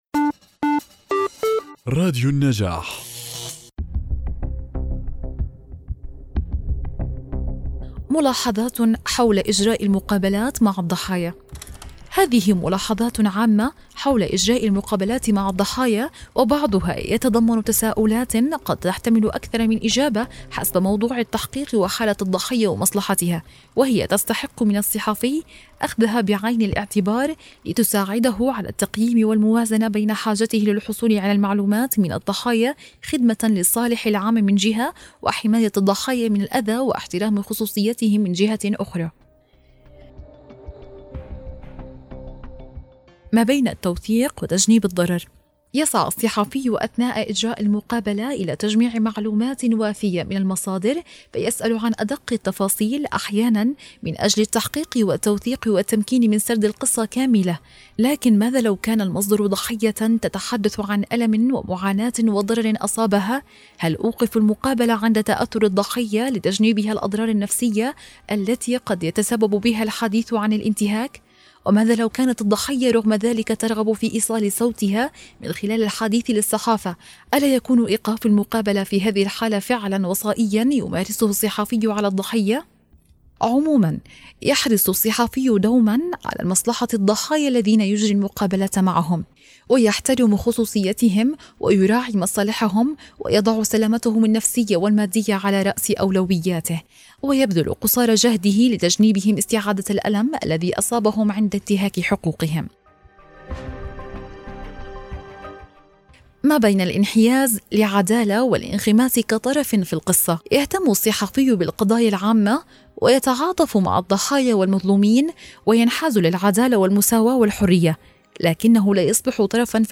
الكتاب المسموع